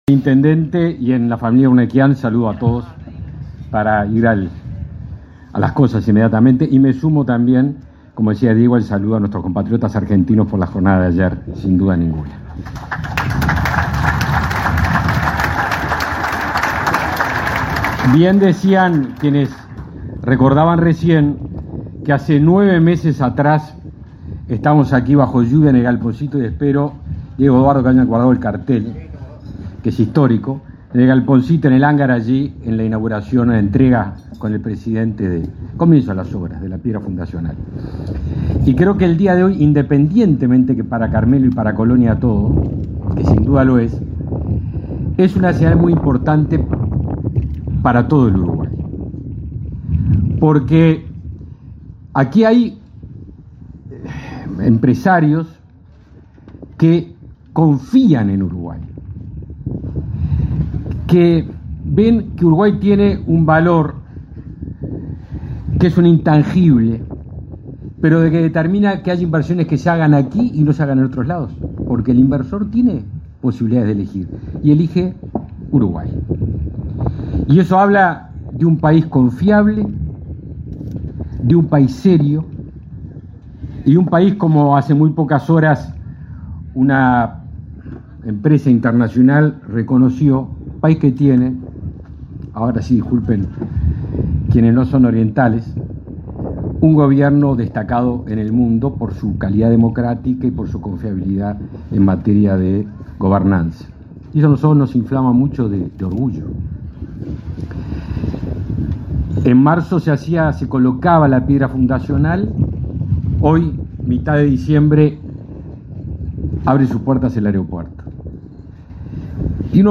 Palabras del ministro de Defensa Nacional, Javier García
El 19 de diciembre fue inaugurado el aeropuerto internacional de Carmelo, con la presencia del presidente de la República, Luis Lacalle Pou.
El ministro de Defensa Nacional, Javier García, realizó declaraciones.